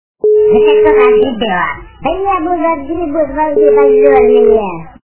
» Звуки » Смішні » Масяня - Щас отгребу